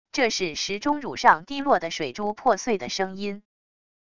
这是石钟乳上低落的水珠破碎的声音wav音频